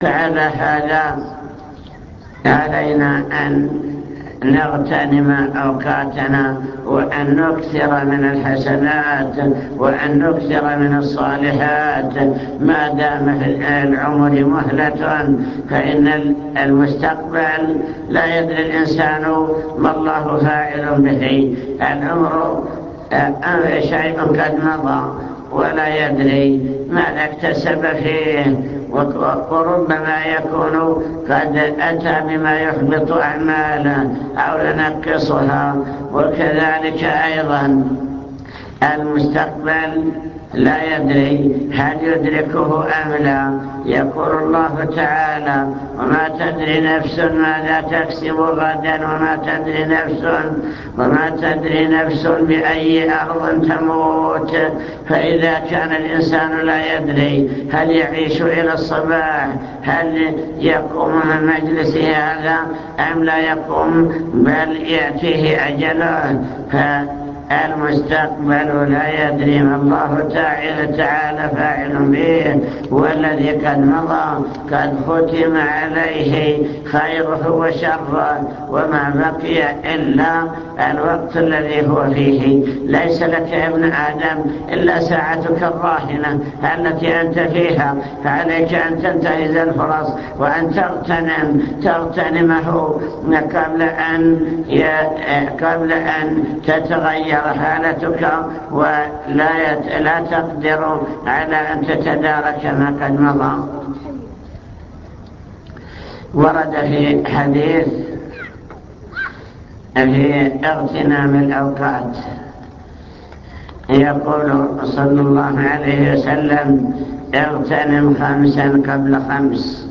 المكتبة الصوتية  تسجيلات - لقاءات  لقاء مفتوح بمخيم الردف